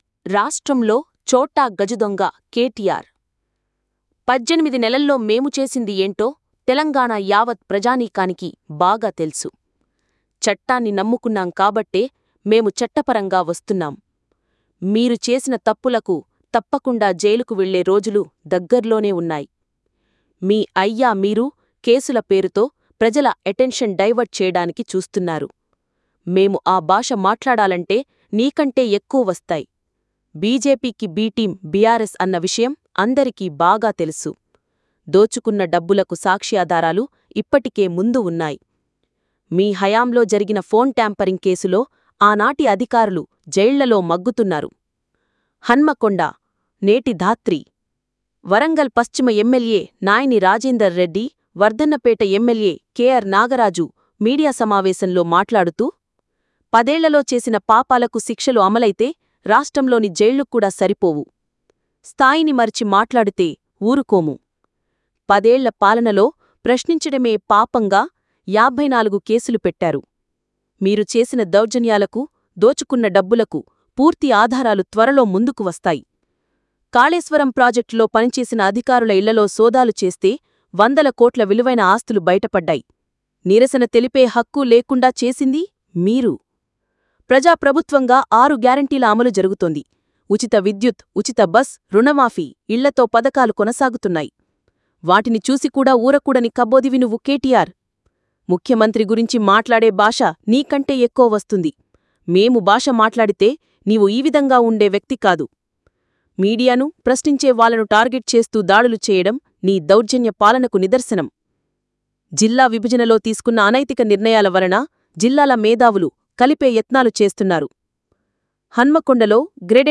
మీడియా సమావేశంలో ఎమ్మెల్యే నాయిని రాజేందర్ రెడ్డి ,కే ఆర్ నాగరాజు…